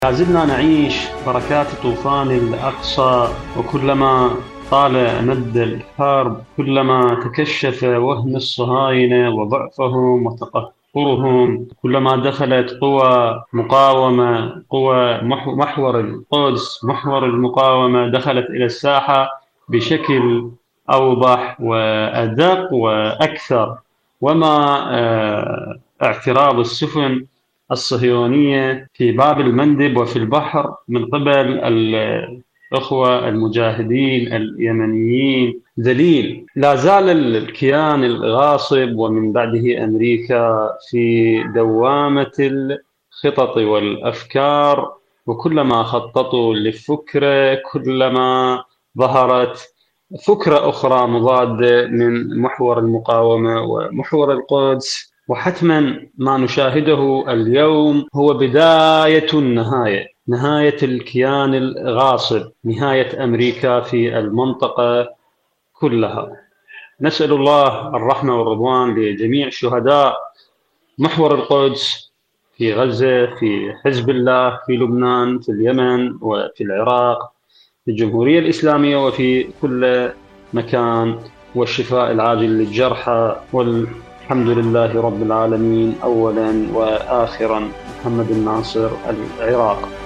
البث المباشر